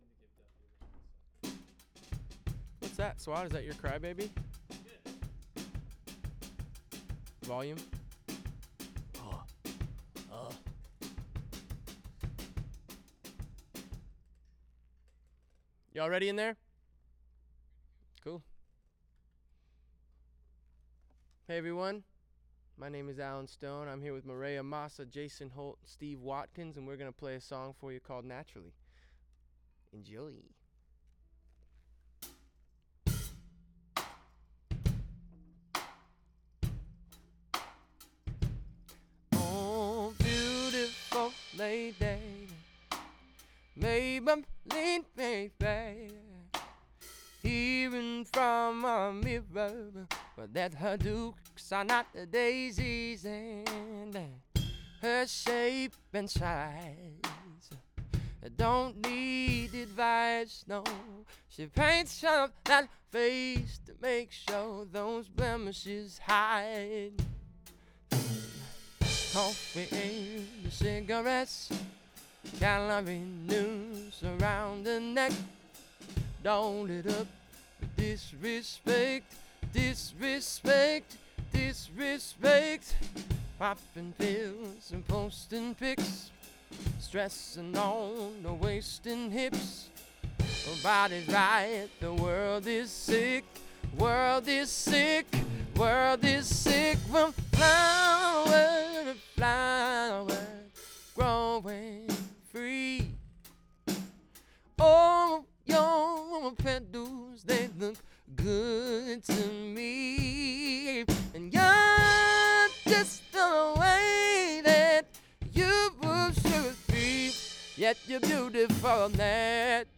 Нужна помощь с вокалом
Все бы ничего, да вот ни как не совладаю с вокалом лидирующим. Не могу понять, то ли это особенности данного вокалиста, то ли это особенности микрофона, но частотно я не могу привести его в норму.
Вложения Vocals-Lead-U47.wav Vocals-Lead-U47.wav 42,5 MB · Просмотры: 477